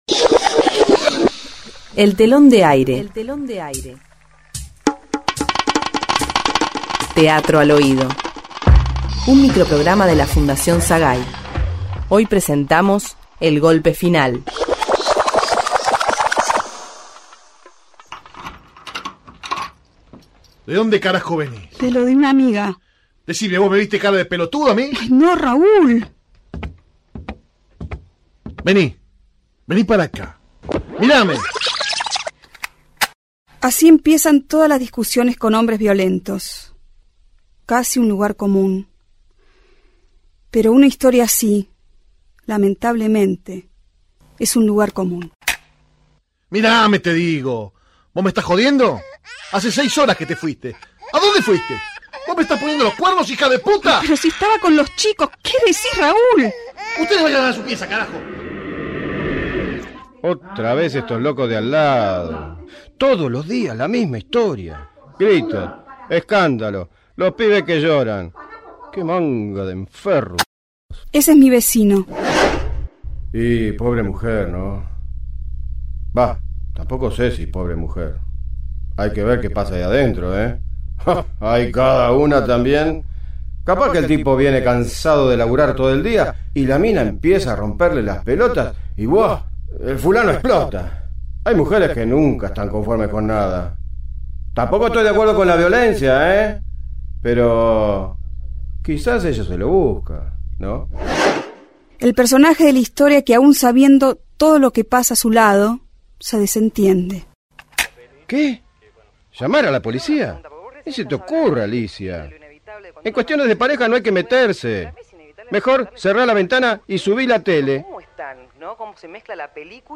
Título: El golpe final. Género: Ficción. Sinopsis: La historia repetida, que puede dar un giro… desesperado y final.